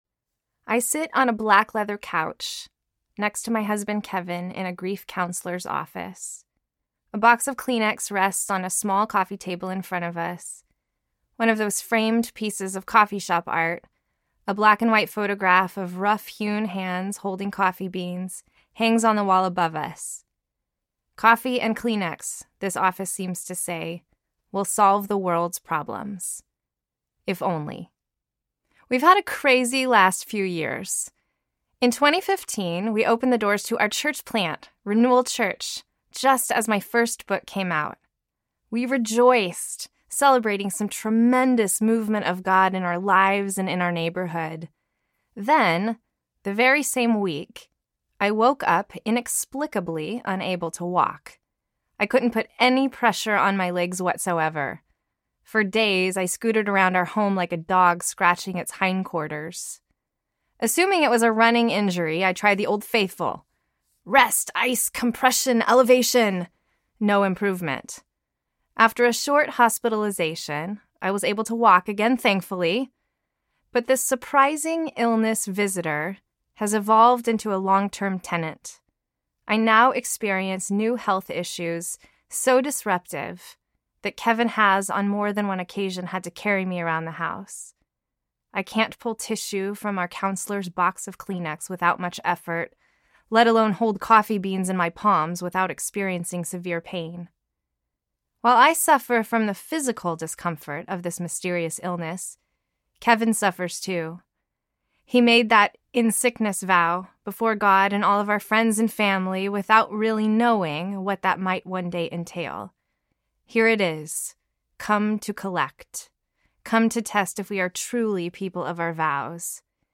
The Louder Song Audiobook
5.1 Hrs. – Unabridged